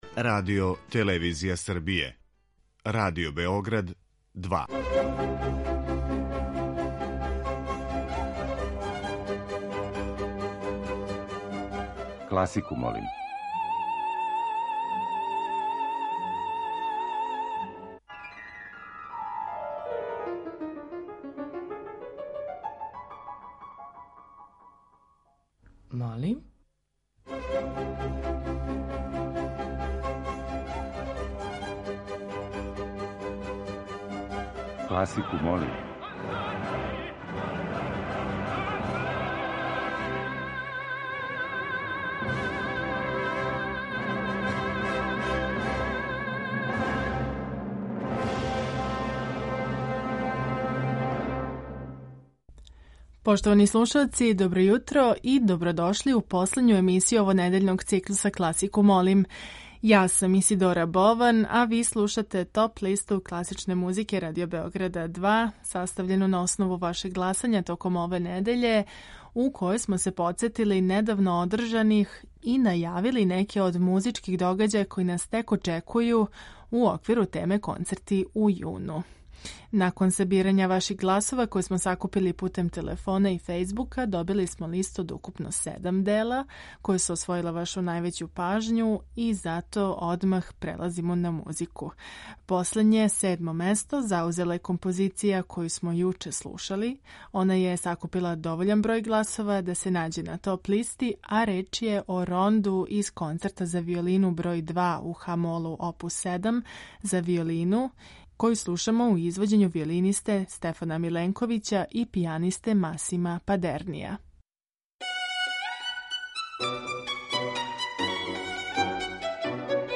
Топ-листа класичне музике
klasika.mp3